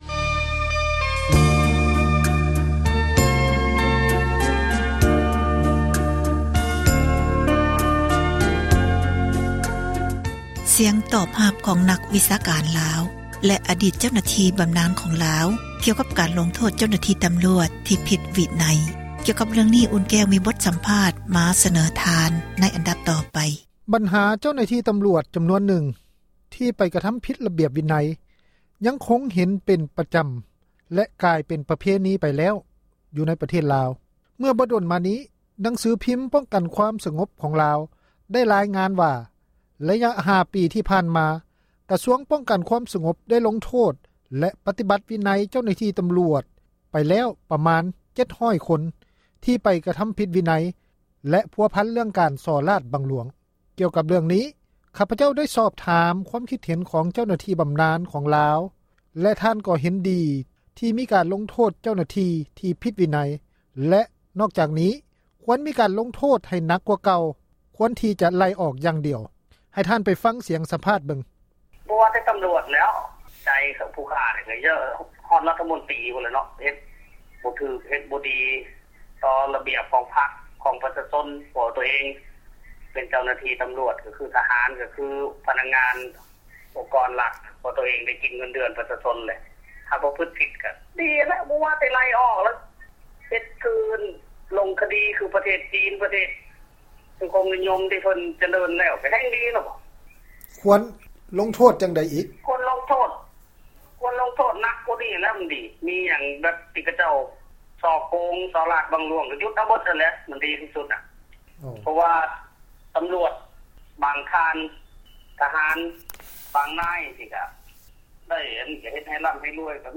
ສຽງຕອບຮັບ ຂອງ ນັກວິຊາການ ລາວ ແລະ ເຈົ້າໜ້າທີ່ ບຳນານ ຂອງລາວ ກ່ຽວກັບ ການລົງໂທດ ເຈົ້າໜ້າທີ່ ຕຳຣວດ ທີ່ ຜິດ ຕໍ່ວິນັຍ.